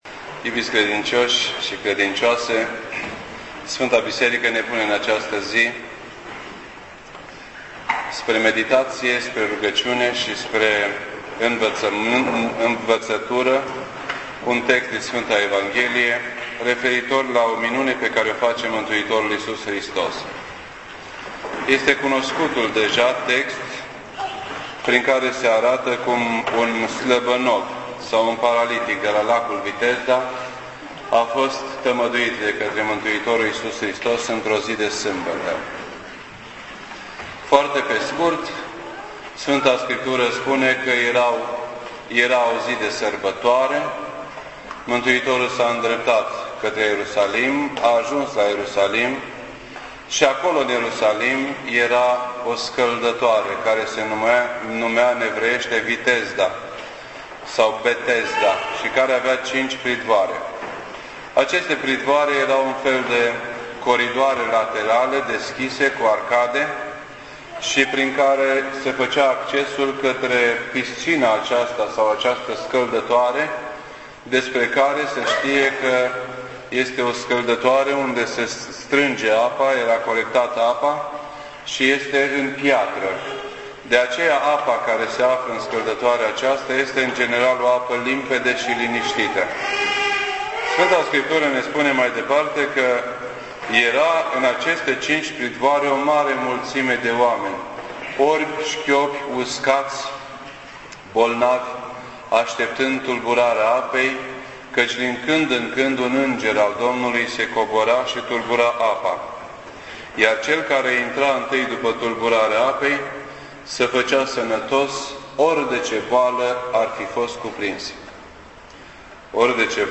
This entry was posted on Sunday, May 10th, 2009 at 6:50 PM and is filed under Predici ortodoxe in format audio.